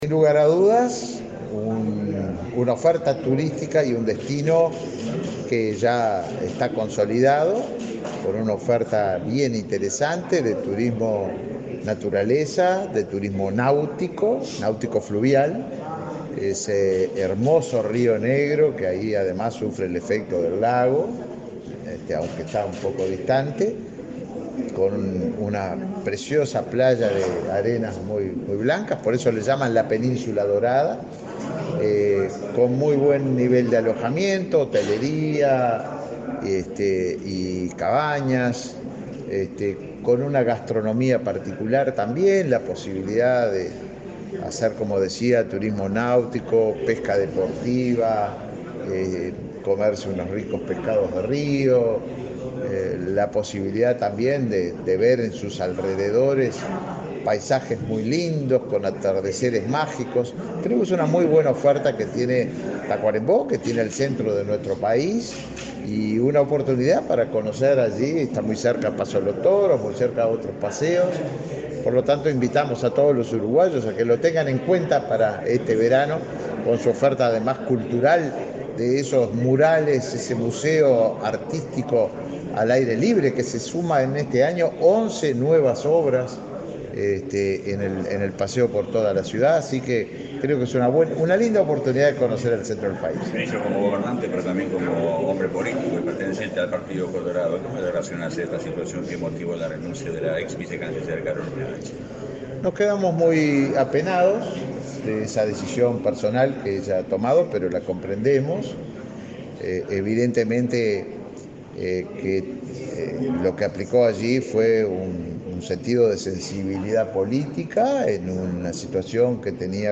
Declaraciones del ministro de Turismo, Tabaré Viera
Declaraciones del ministro de Turismo, Tabaré Viera 20/12/2022 Compartir Facebook X Copiar enlace WhatsApp LinkedIn El ministro de Turismo, Tabaré Viera, participó este martes 20 en la sede de su cartera ministerial, del lanzamiento de la temporada de verano en la localidad de San Gregorio de Polanco, departamento de Tacuarembó. Antes, dialogó con la prensa.